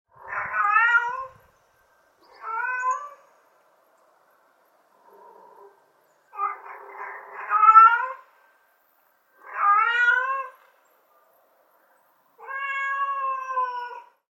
Mother Cat Calling For Her Kittens Sound Effect
Animal Sounds / Cat Meow Sound / Sound Effects
Mother-cat-calling-for-her-kittens-sound-effect.mp3